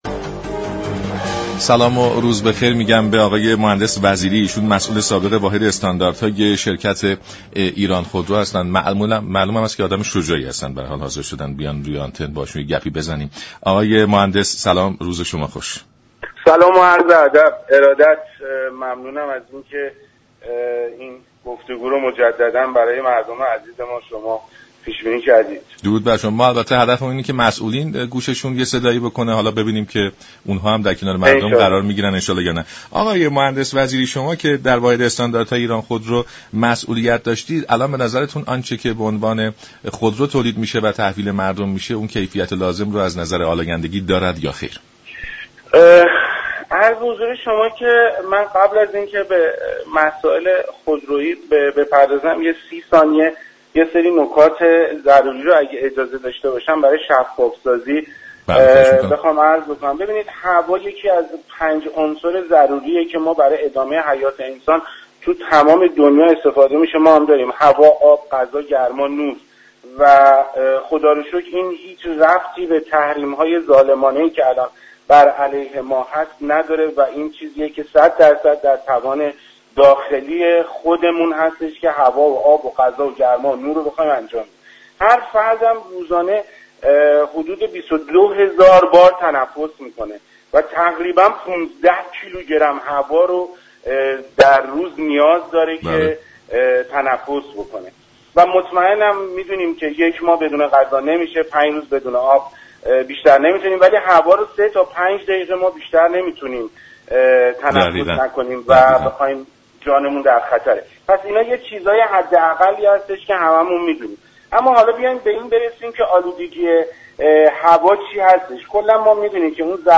گفت و گو با رادیو ایران